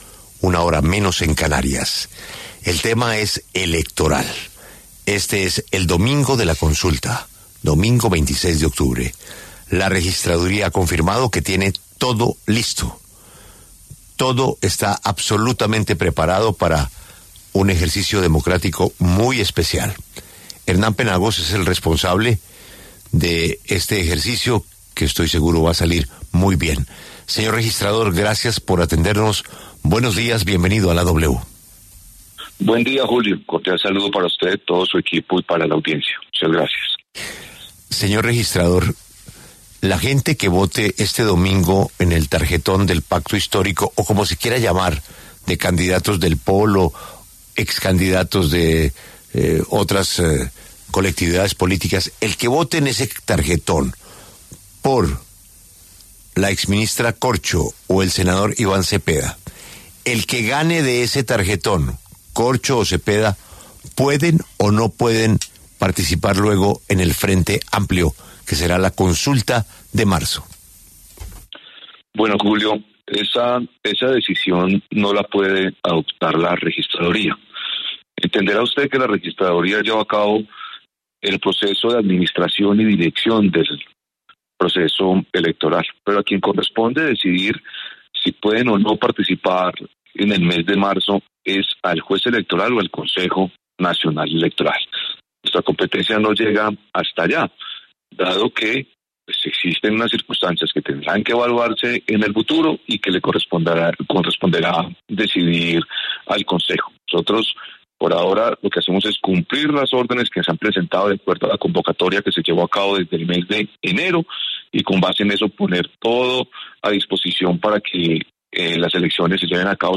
Hernán Penagos, registrador nacional, pasó por los micrófonos de La W, y habló sobre las consultas del Pacto Histórico de este domingo.